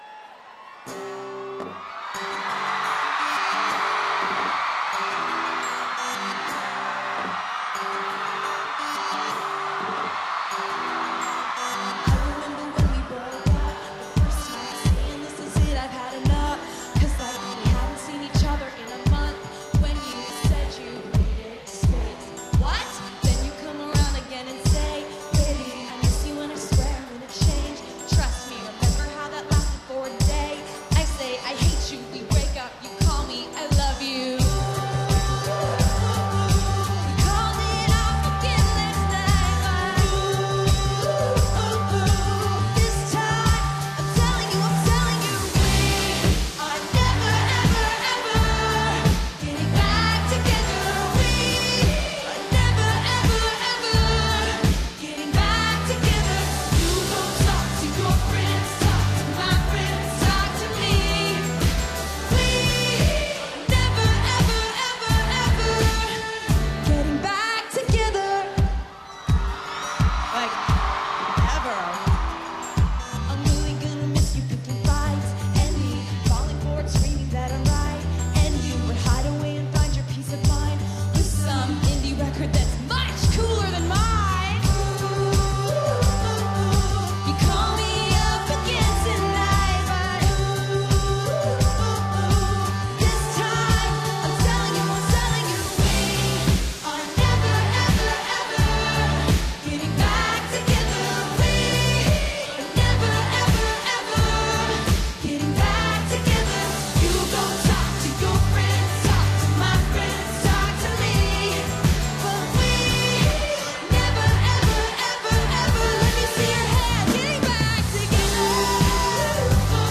recorded May 24, 2015 at Earlham Park, Norwich.
In Concert